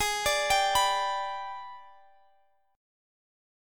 Listen to G/Ab strummed